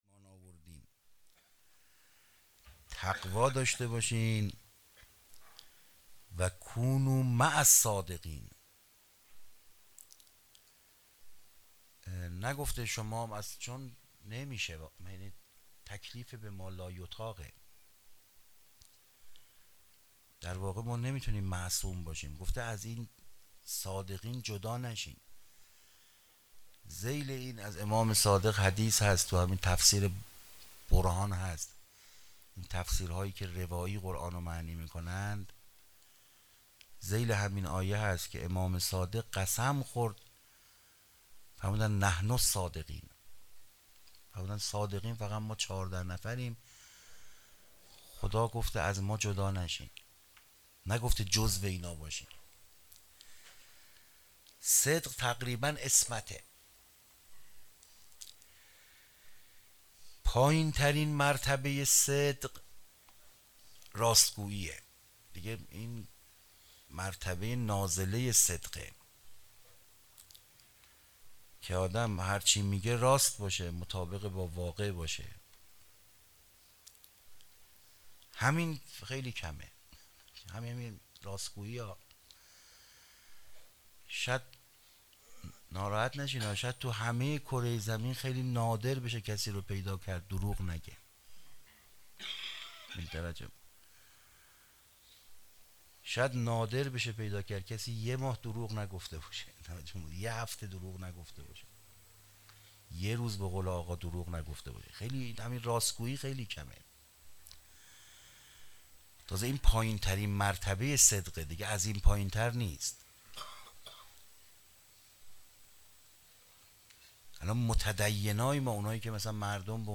سخنرانی روز نهم